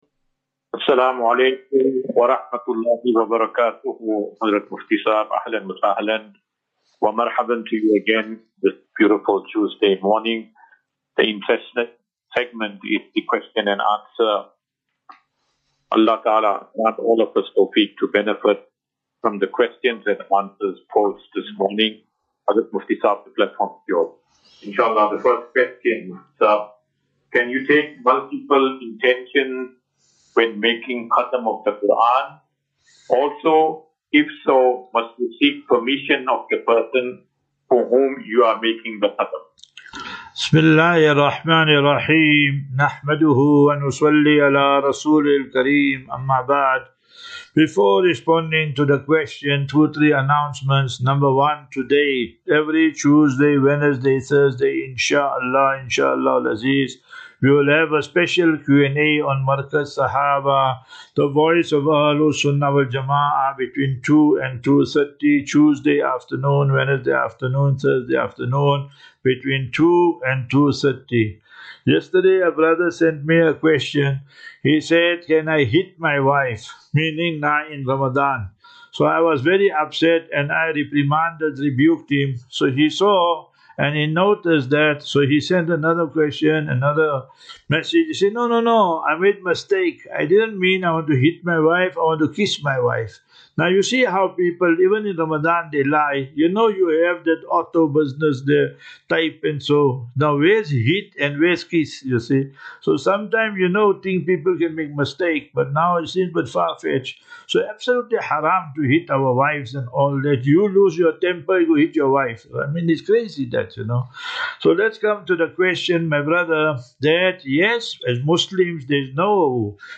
View Promo Continue Install As Safinatu Ilal Jannah Naseeha and Q and A 4 Mar 04 March 2025.